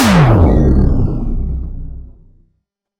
Warp Drive
A warp drive engaging with building energy, dimensional shift, and velocity burst
warp-drive.mp3